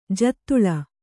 ♪ jattuḷa